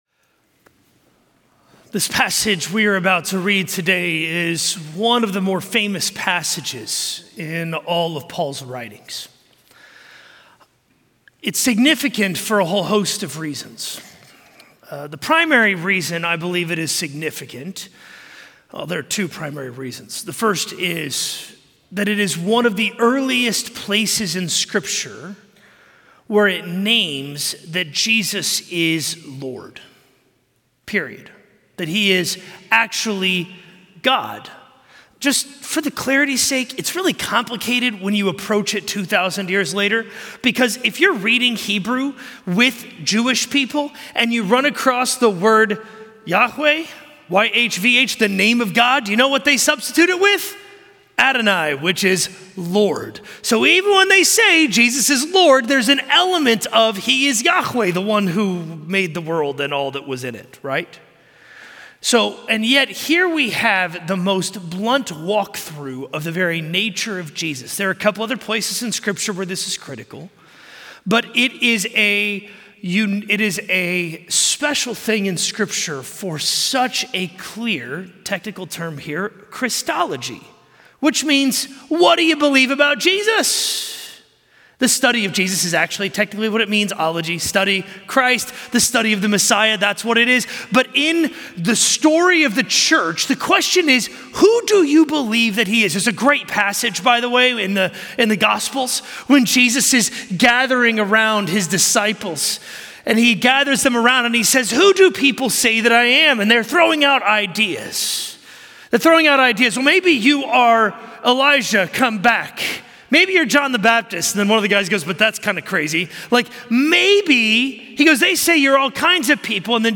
A message from the series "Dear Church."